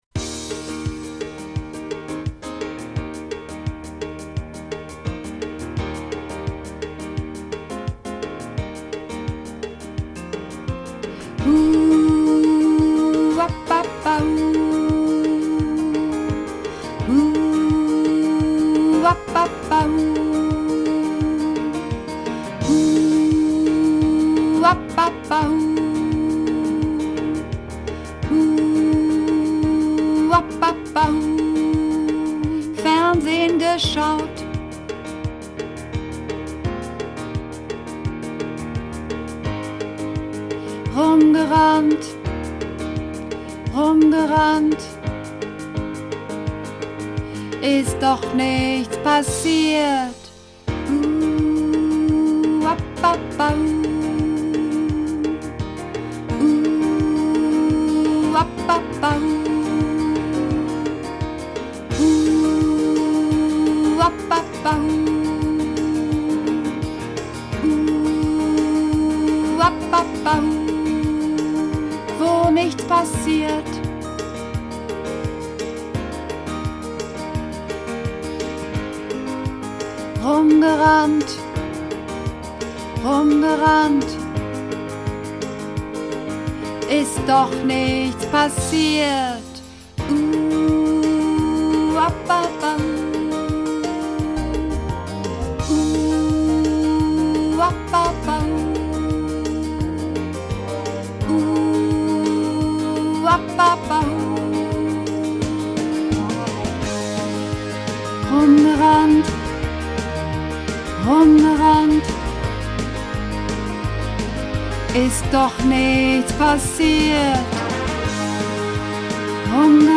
Übungsaufnahmen - Langeweile
Langeweile (Männer)
Langeweile__3_Maenner.mp3